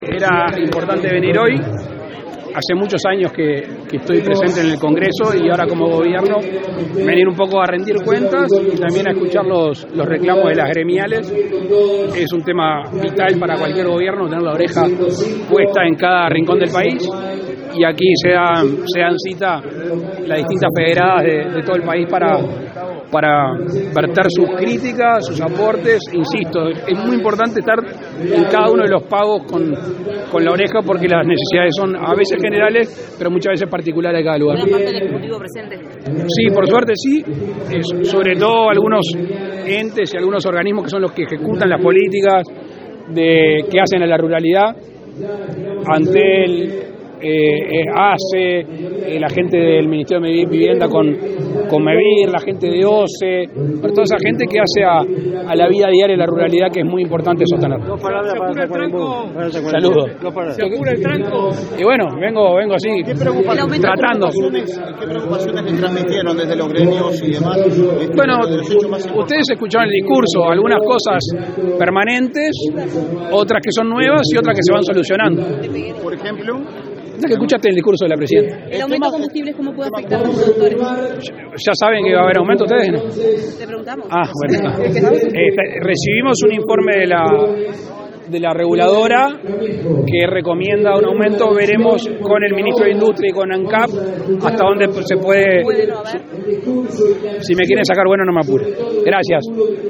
Declaraciones del presidente de la República, Luis Lacalle Pou, a la prensa
Tras participar en la clausura del 105.º Congreso de la Federación Rural, este 28 de mayo en Tacuarembó, el presidente Lacalle Pou efectuó